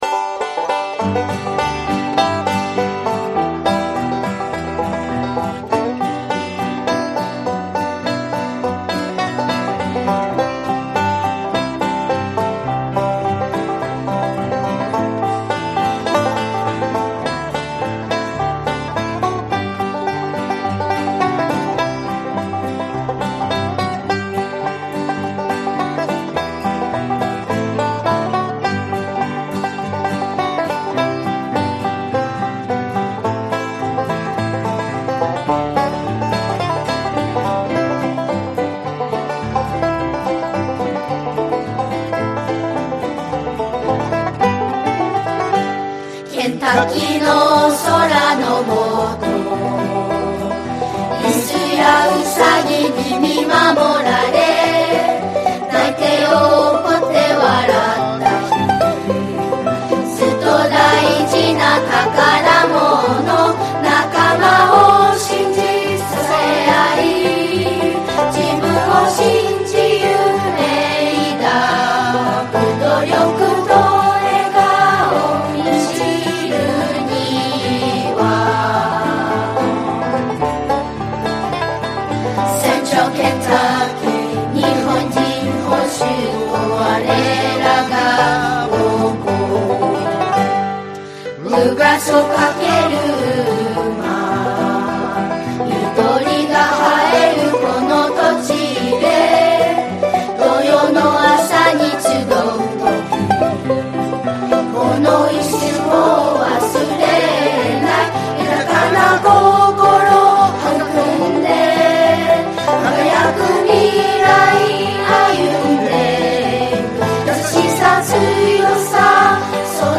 ・CDの最後に生徒の笑い声が入っているのもめずらしい。